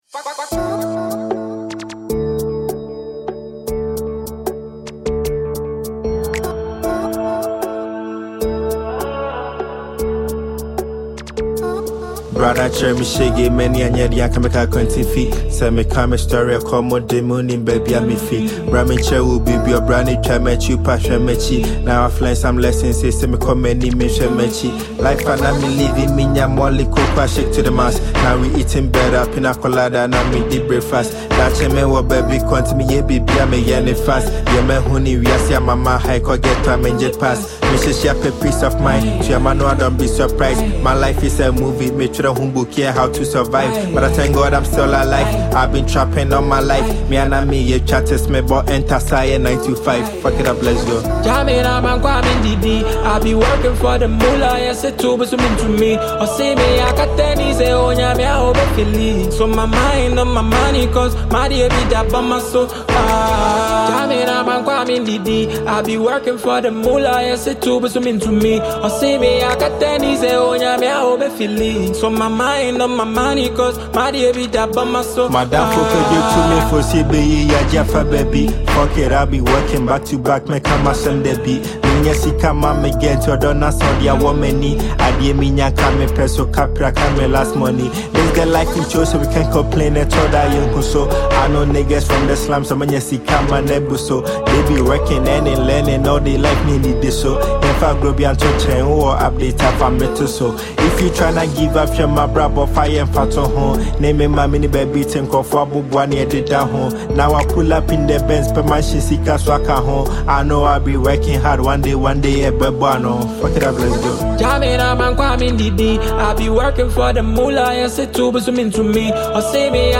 pure drill energy